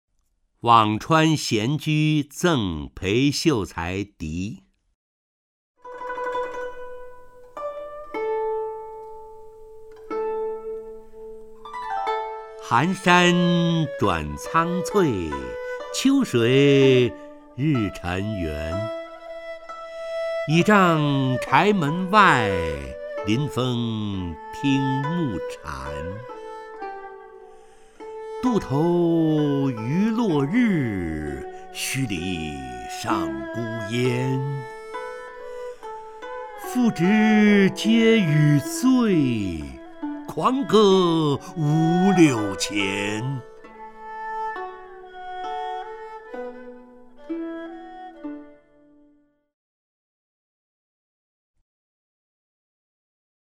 陈醇朗诵：《辋川闲居赠裴秀才迪》(（唐）王维)
名家朗诵欣赏 陈醇 目录
WangChuanXianJuZengPeiXiuCaiDi_WangWei(ChenChun).mp3